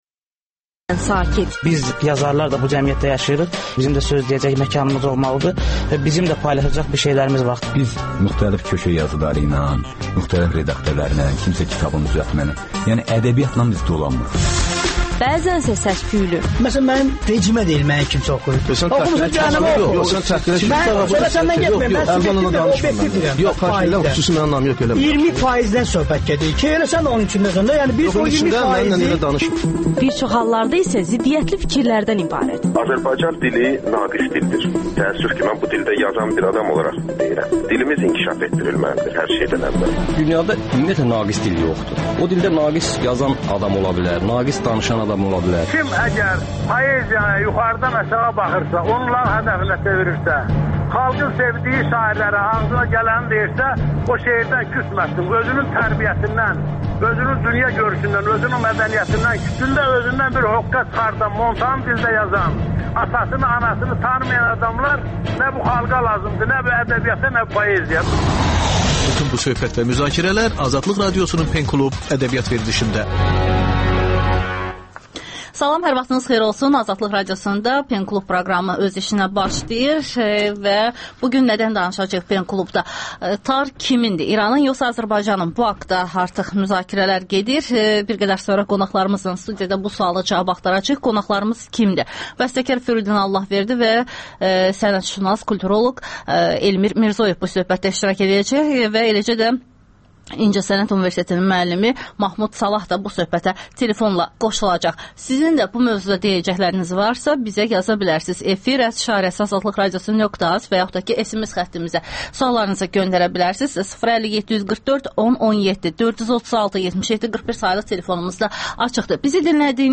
radiodebatı